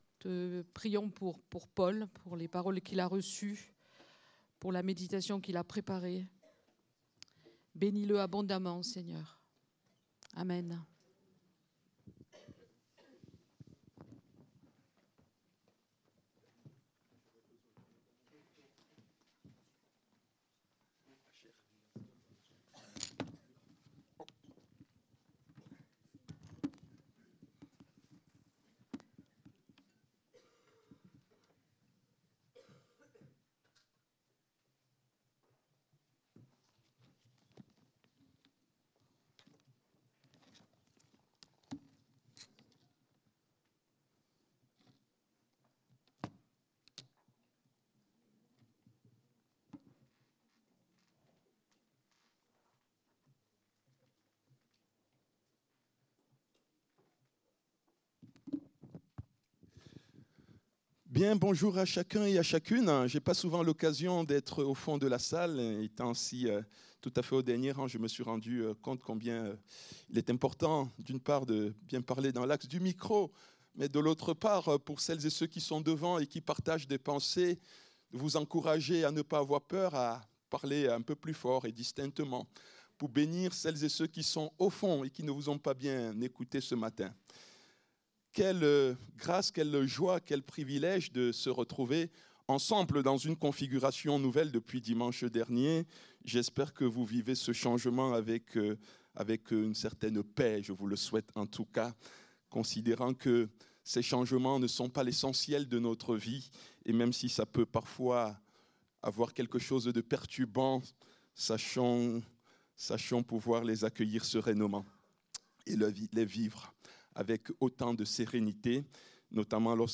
Culte du dimanche 09 mars 2025